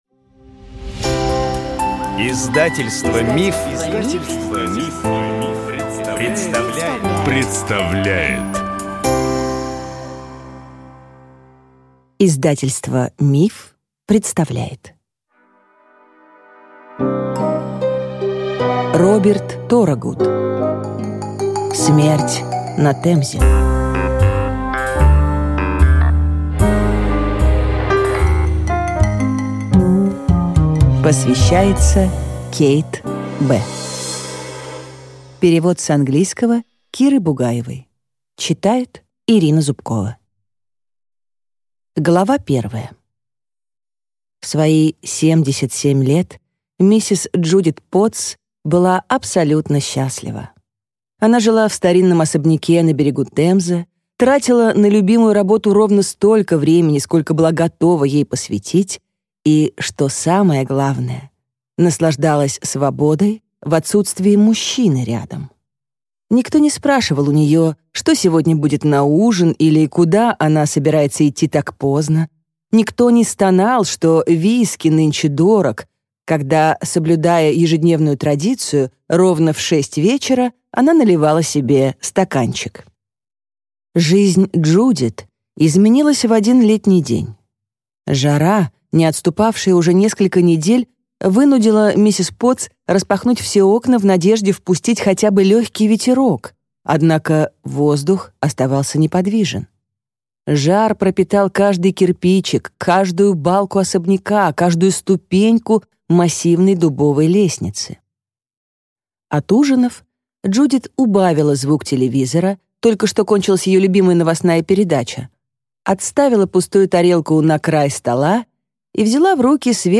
Аудиокнига Смерть на Темзе | Библиотека аудиокниг